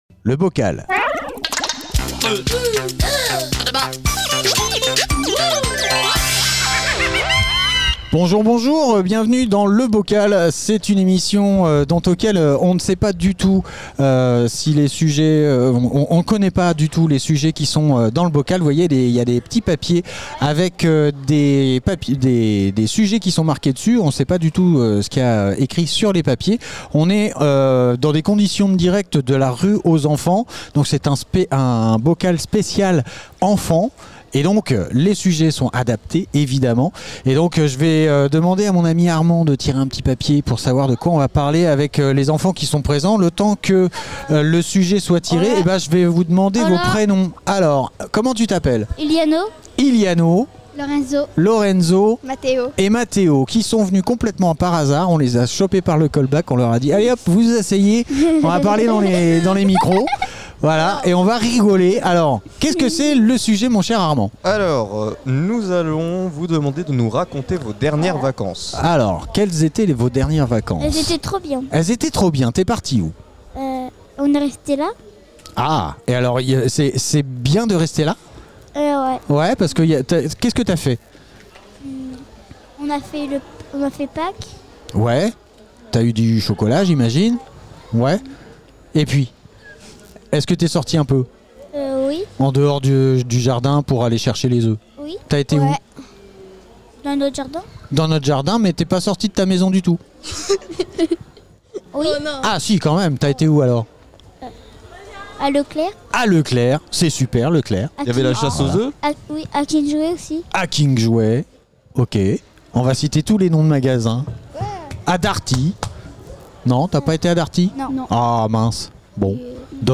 La rue aux enfants Interview enfants fécamp associations association rue aux enfants Bocal